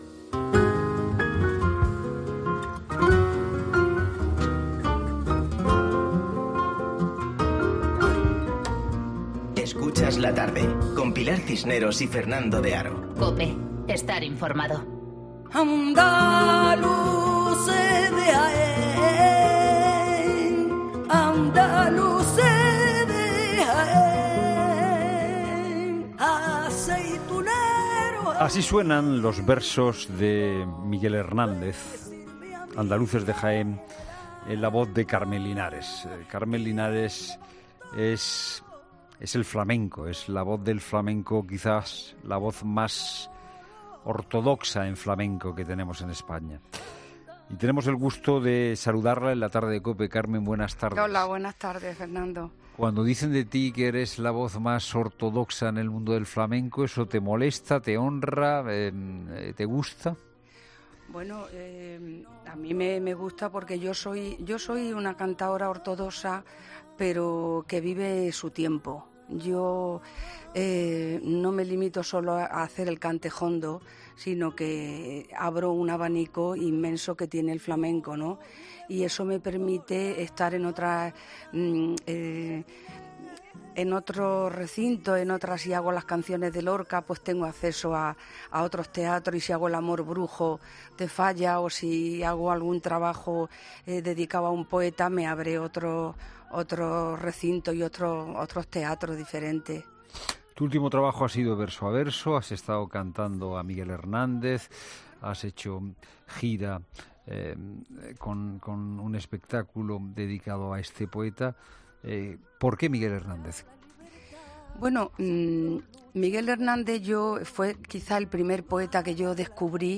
Esta que escuchan es la voz de la cantaora Carmen Linares, la única mujer del flamenco que ha ganado el Premio Nacional de Música de España en la categoría de interpretación y una de las maestras del cante jondo .
Carmen Linares explica ante los micrófonos de la Cadena COPE que se considera una una cantaora ortodoxa pero que vive su tiempo .